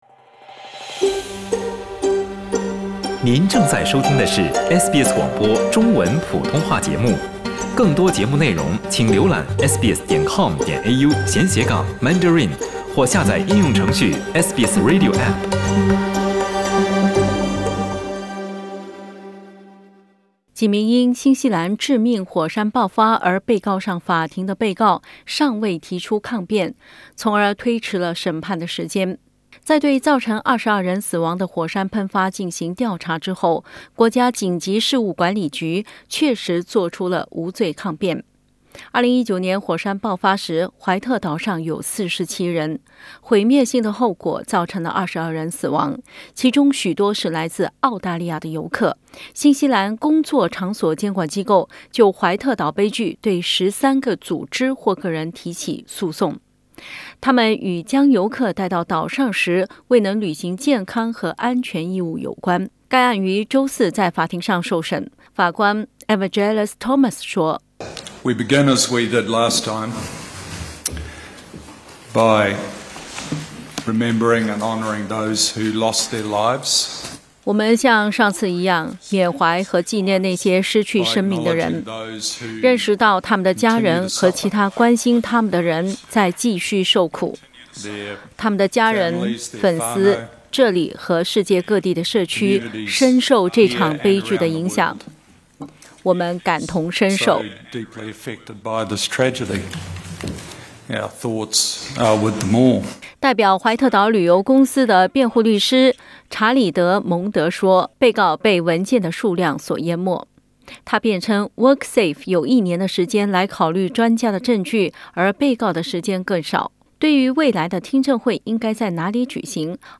（請聽報道） 澳大利亞人必鬚與他人保持至少1.5米的社交距離，請查看您所在州或領地的最新社交限制措施。